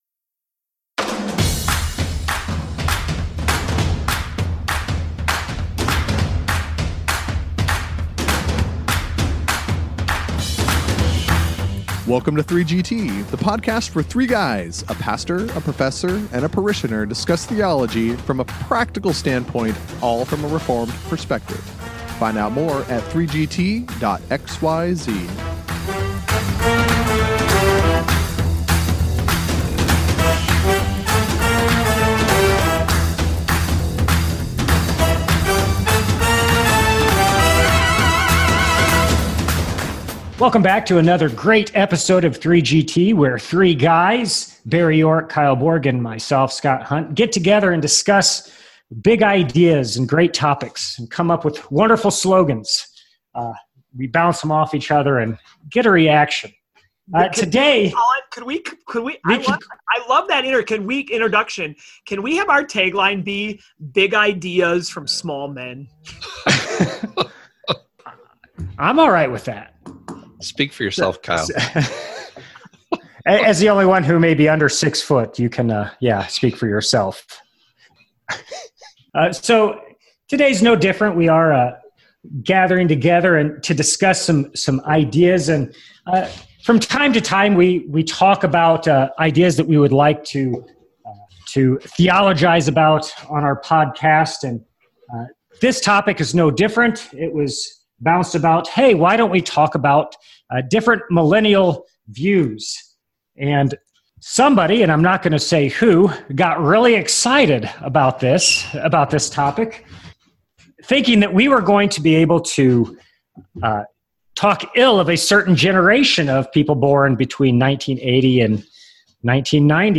This 3GT episode begins with a couple of the hosts trying to figure out the topic of the day.